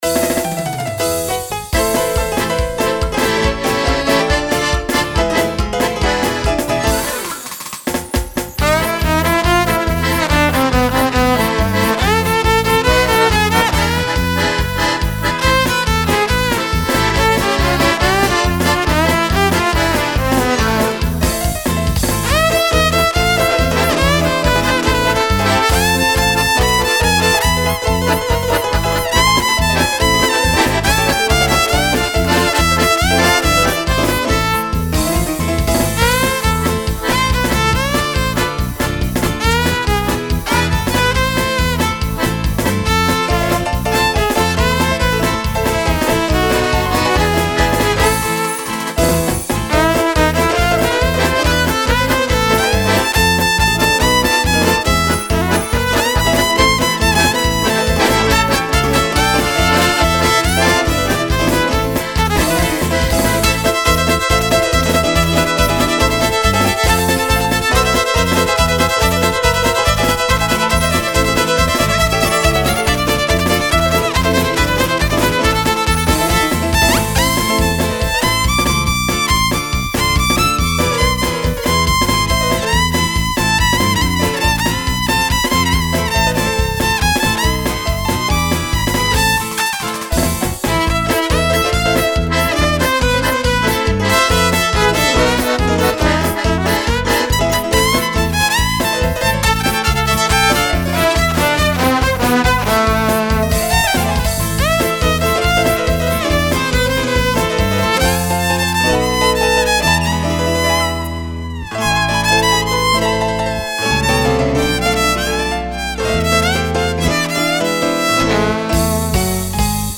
эстрадная скрипка